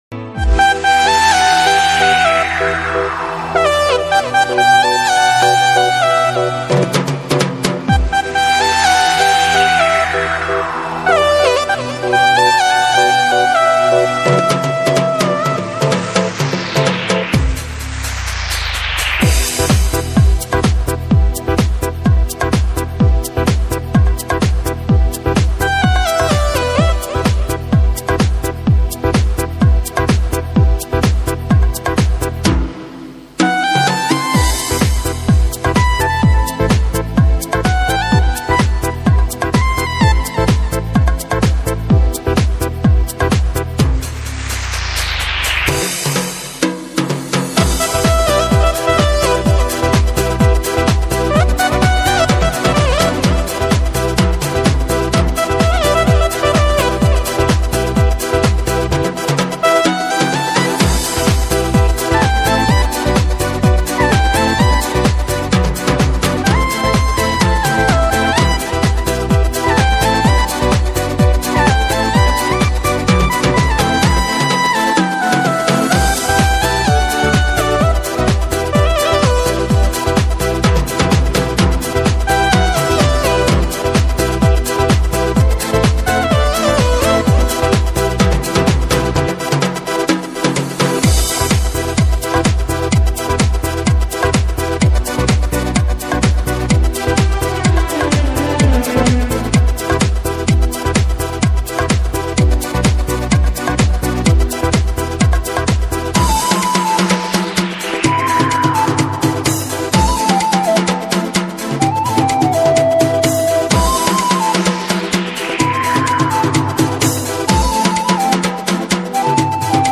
Worship songs